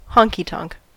Ääntäminen
IPA : /ˈhɒŋkiˌtɒŋk/